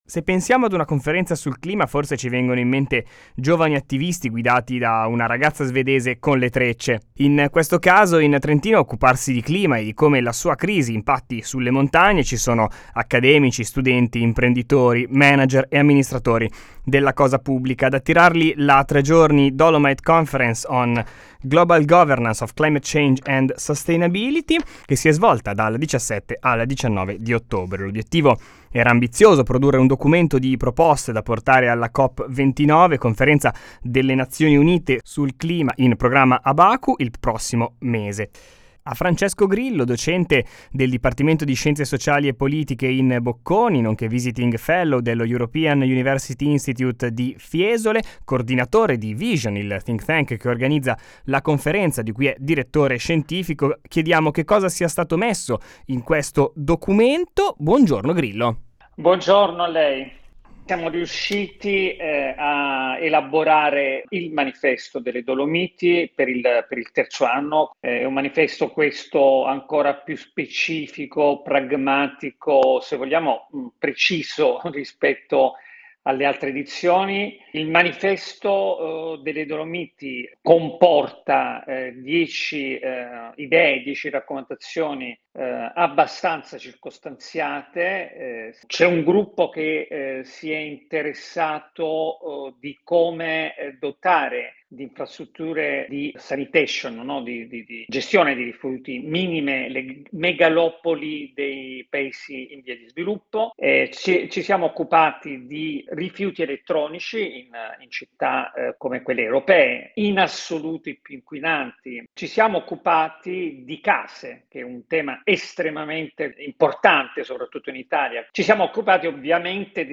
Intervista 19.10.2024, Radio Italia